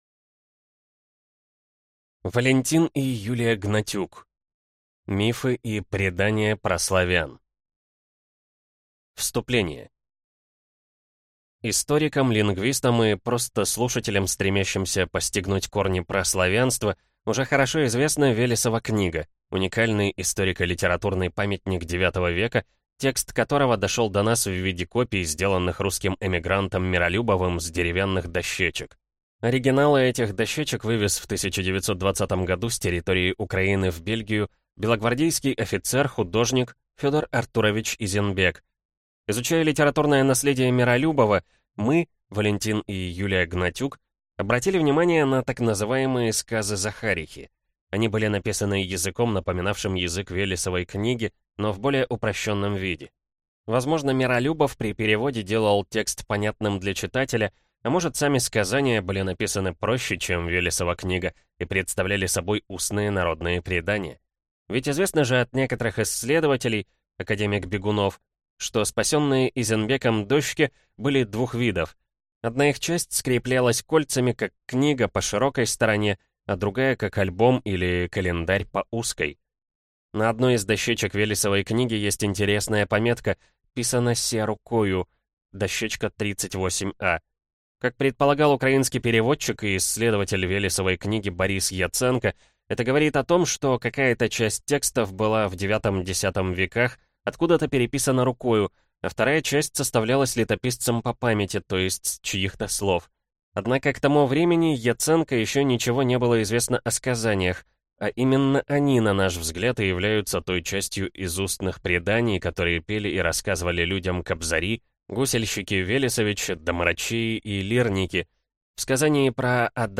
Аудиокнига Мифы и предания праславян | Библиотека аудиокниг
Прослушать и бесплатно скачать фрагмент аудиокниги